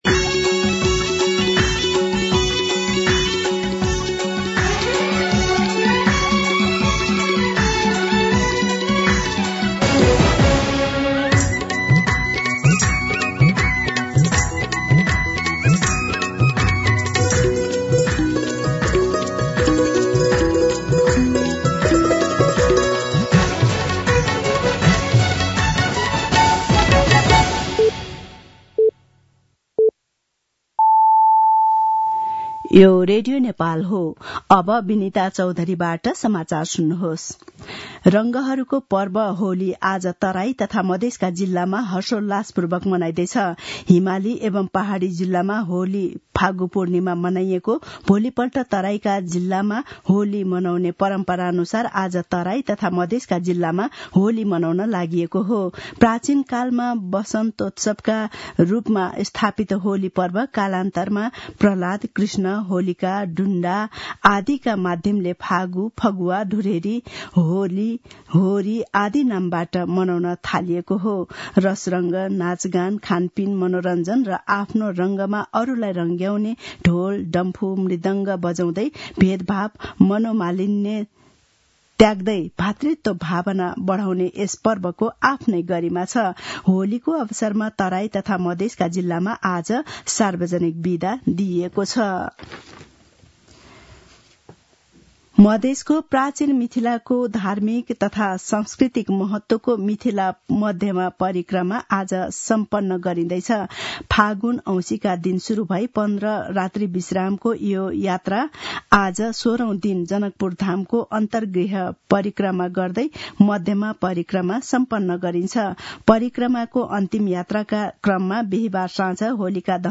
मध्यान्ह १२ बजेको नेपाली समाचार : १ चैत , २०८१